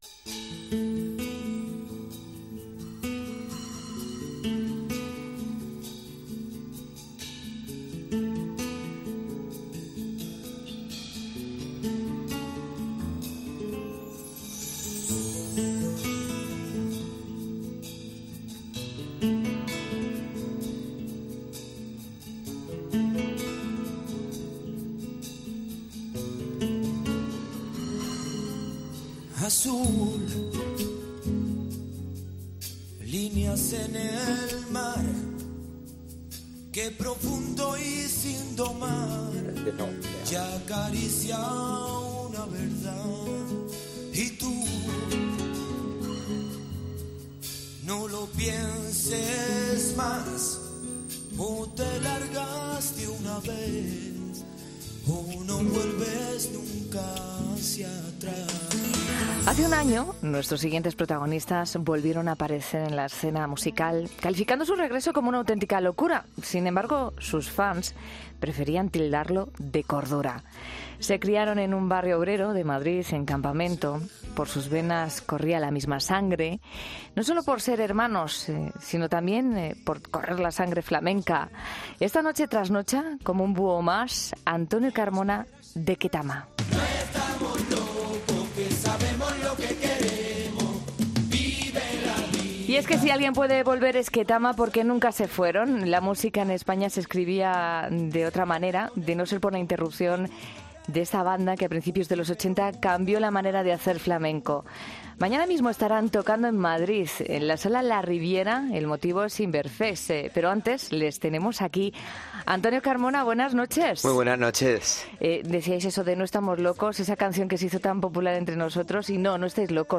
El artista granadino acerca a los micrófonos de la Cadena COPE su visión de la música